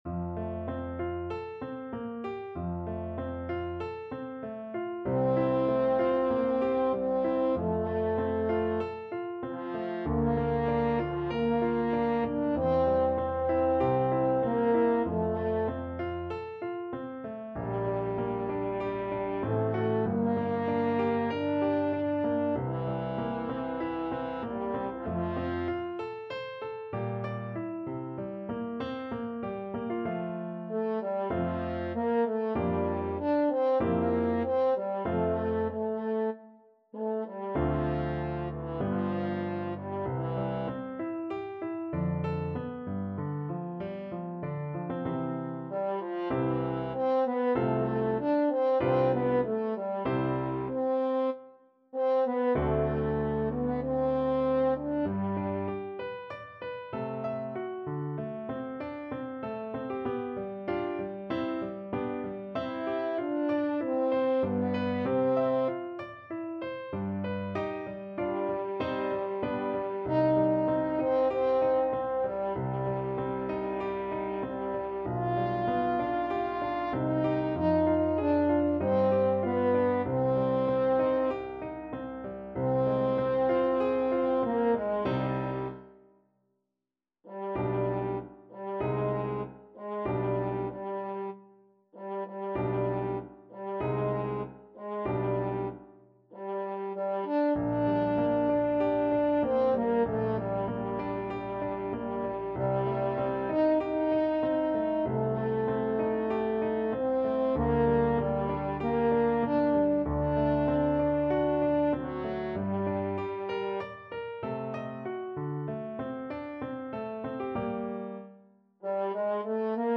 French Horn
F major (Sounding Pitch) C major (French Horn in F) (View more F major Music for French Horn )
~ = 96 Andante
4/4 (View more 4/4 Music)
Classical (View more Classical French Horn Music)